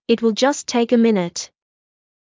ﾉｯﾄ ﾏｯﾁ ﾛﾝｶﾞｰ ﾄｩ ｺﾞｰ